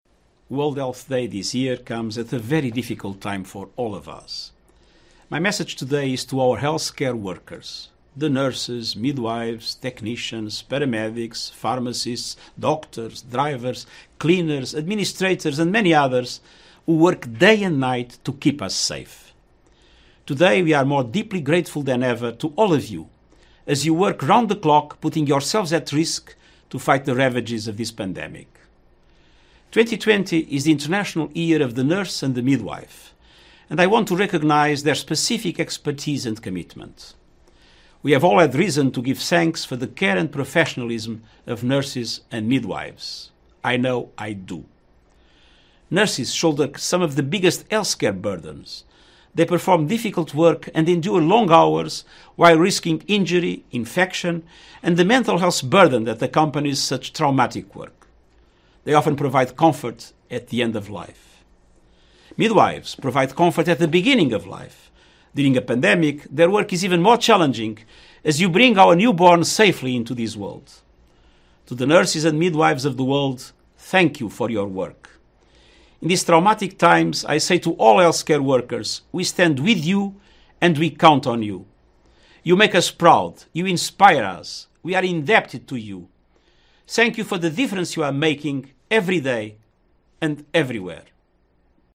UN Secretary General's message for World Health Day
This year’s World health day has been dedicated to nurses and midwives, to celebrate their work and remind world leaders of the critical role they play in keeping the world healthy. In his message, UN secretary General Antonio Guterres commended Nurses and nurses for their role in keeping people safe.